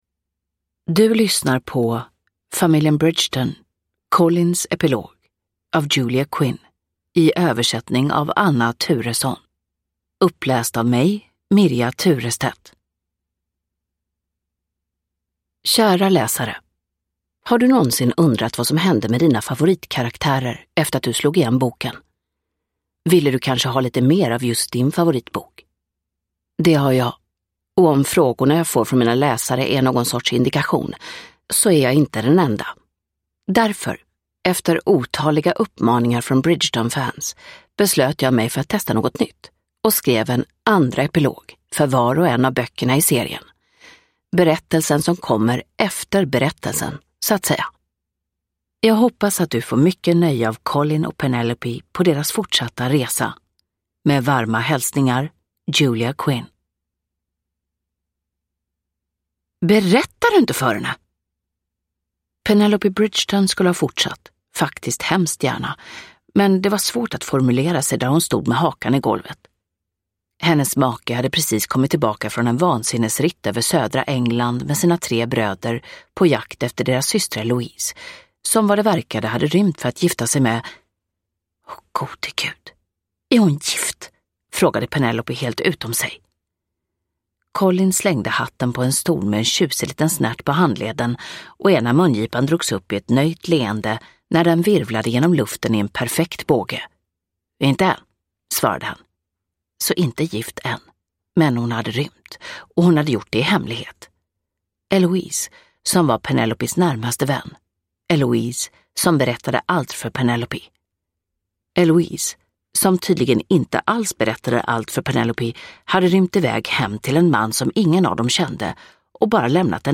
Colins epilog – Ljudbok – Laddas ner